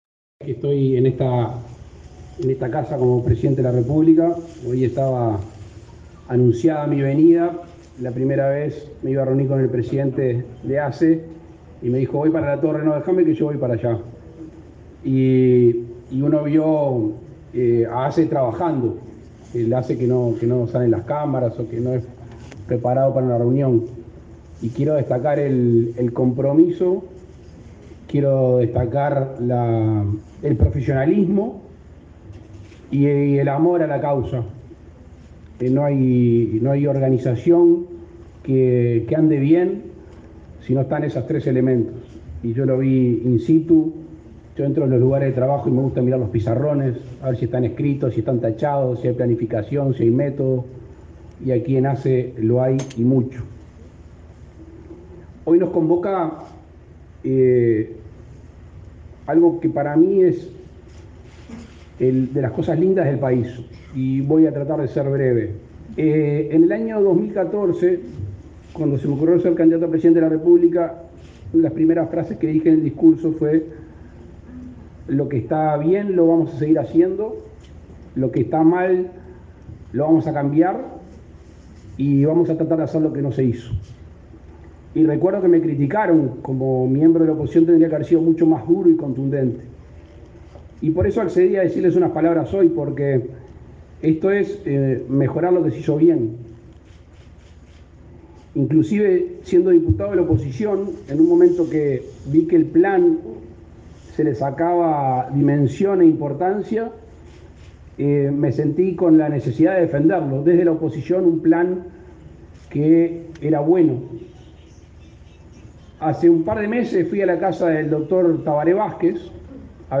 El presidente Luis Lacalle Pou encabezó este viernes 18 la presentación del Plan de Salud Bucal, declarado de interés nacional por el Poder Ejecutivo.